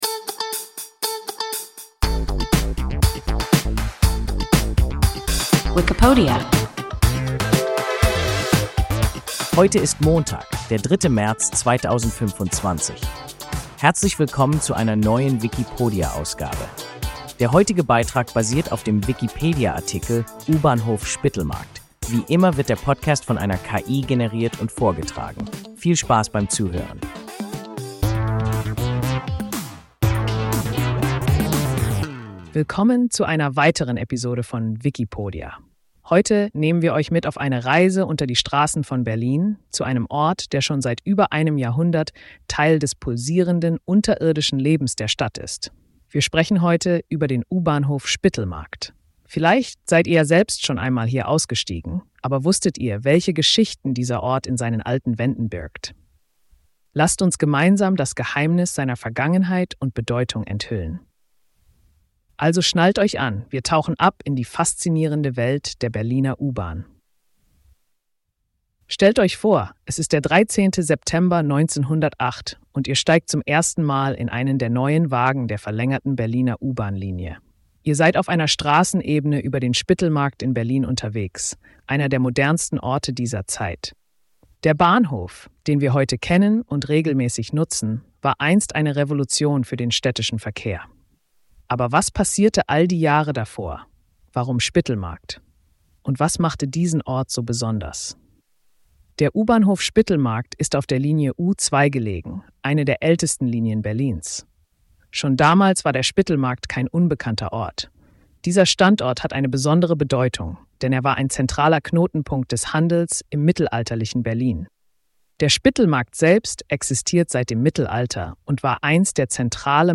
U-Bahnhof Spittelmarkt – WIKIPODIA – ein KI Podcast